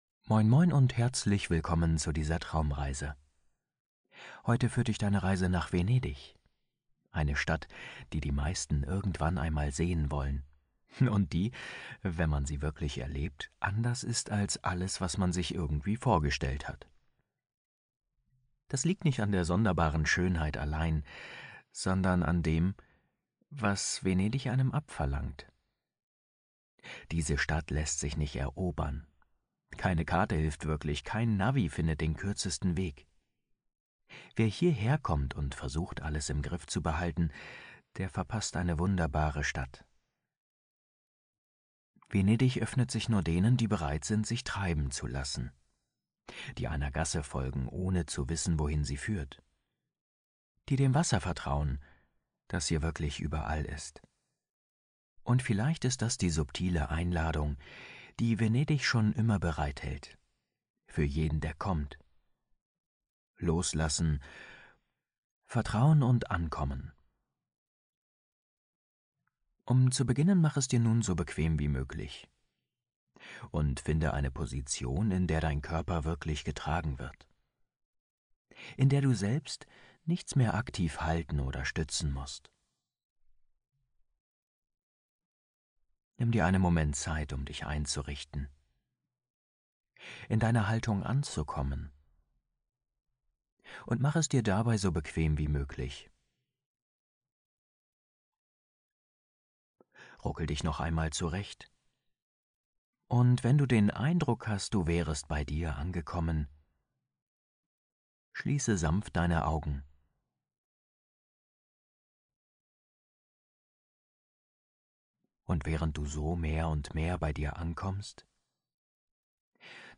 Gleite in dieser Traumreise durch die ruhigen Kanäle Venedigs – begleitet vom sanften Plätschern des Wassers, warmem Abendlicht und leiser italienischer Musik. Lass dich treiben, gib die Kontrolle ab und tauche ein in eine Atmosphäre aus Licht, Stille und Bewegung.